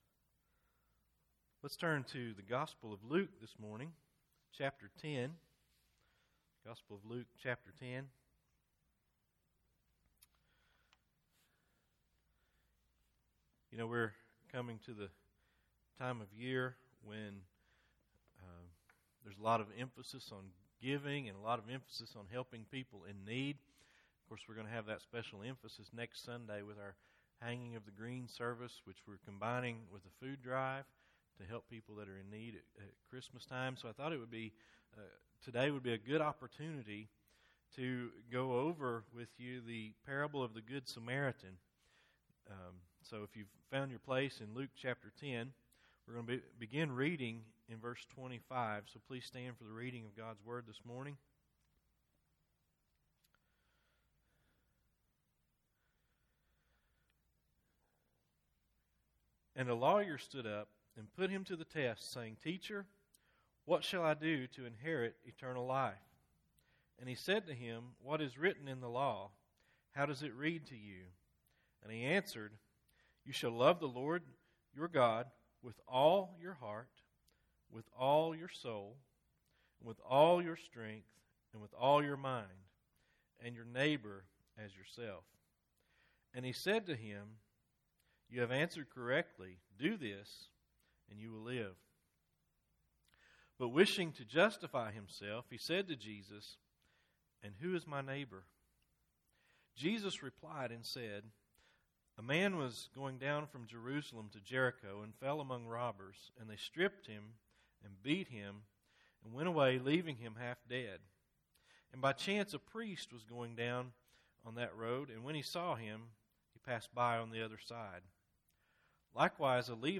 Luke 10:25-37 Service Type: Sunday Morning Service Bible Text